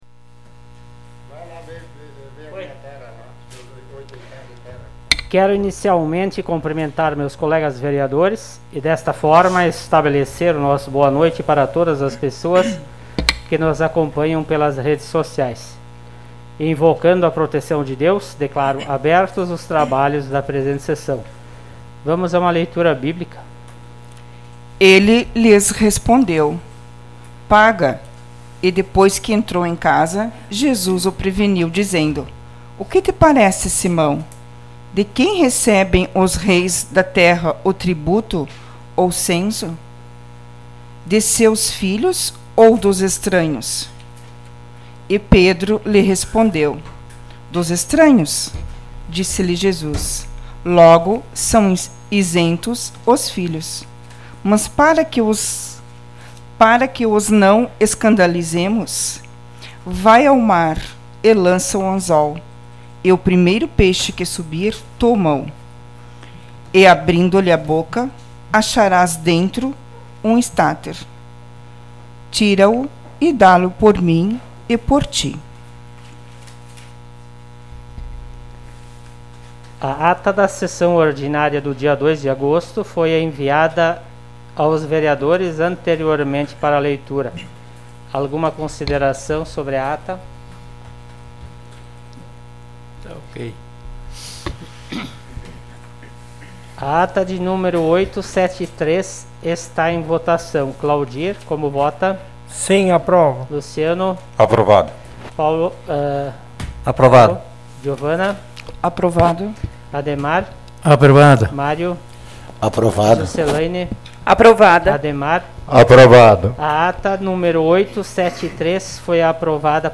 Sessão Ordinária do dia 09 de agosto de 2021